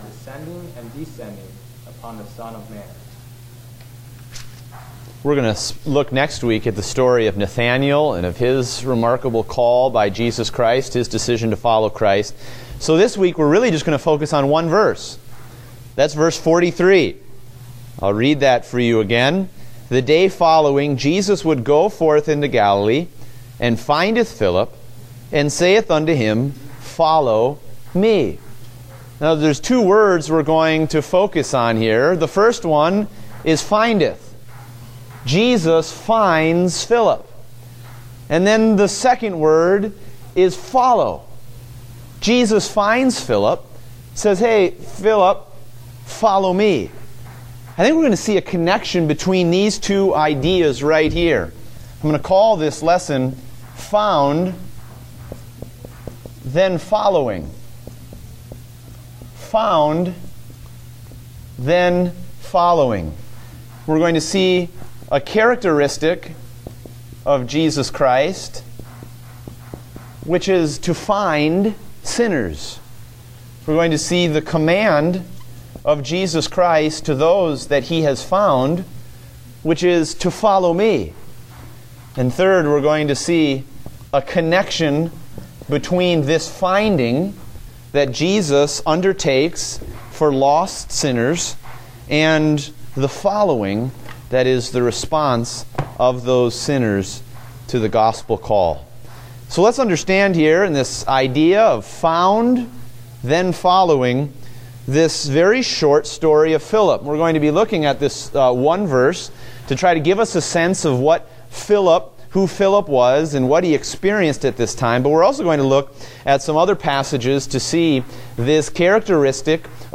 Date: May 1, 2016 (Adult Sunday School)